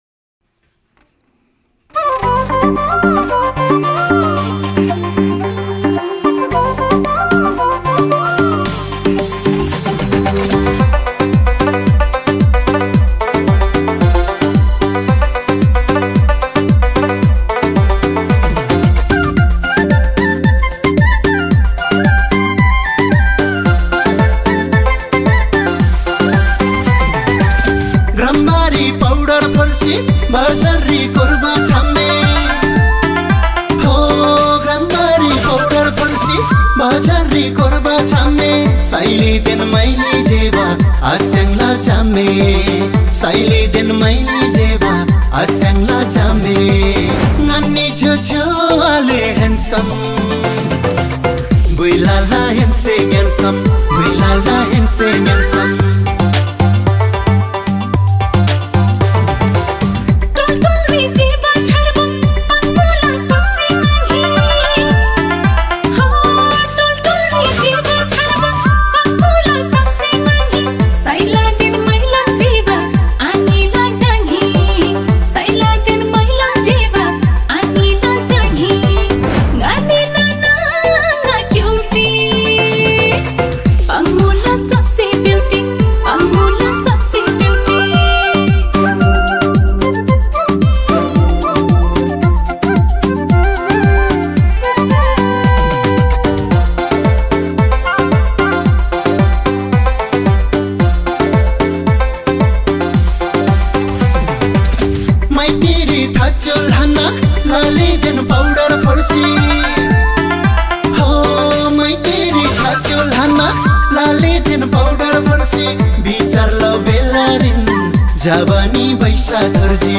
Tamang Selo